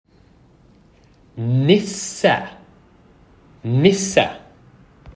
The Norwegian word “nisse” is pronounced as /nɪsə/.
• /ə/: This is a short and neutral vowel sound, known as a schwa, as in the second syllable of “sofa” or “banana.”
So, when pronounced in Norwegian, “nisse” would sound like “niss-uh,” with the stress on the first syllable.